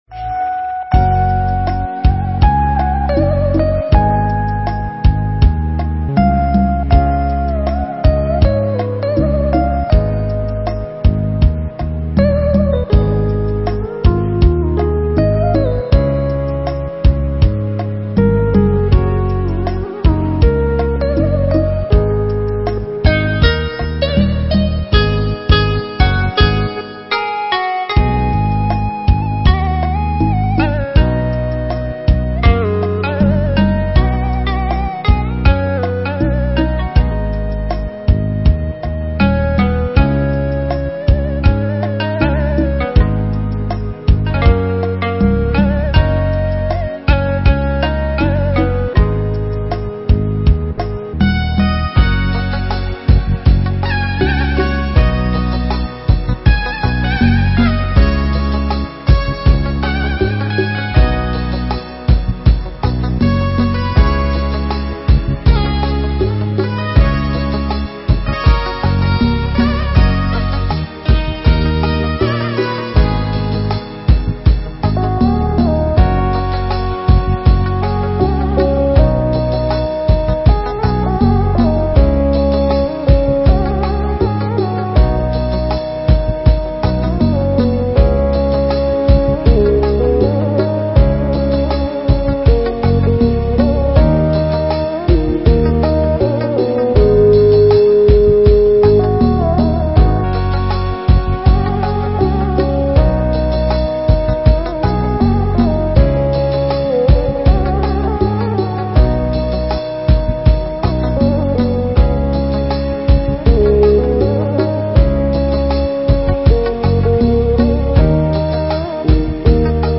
Pháp Thoại
giảng tại chùa Hương Lan (Hà Tây – Hà Nội)